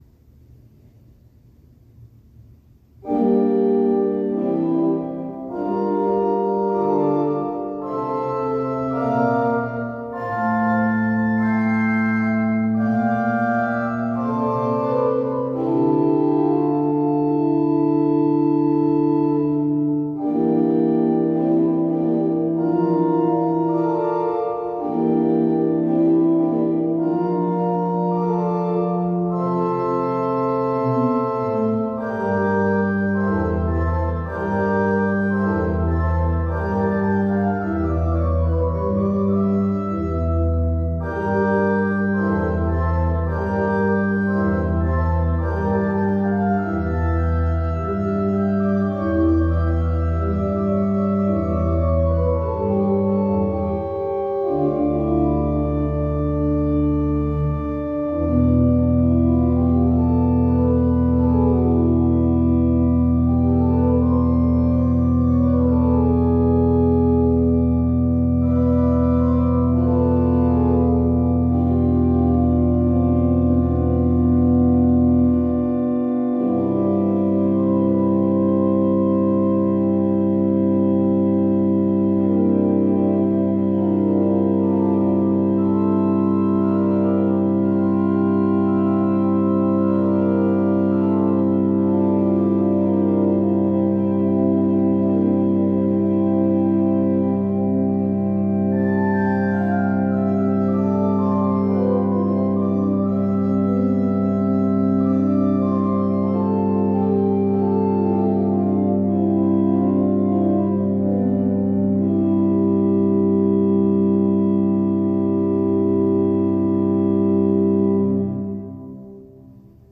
Komposition für Organisten mit verletztem rechten Bein I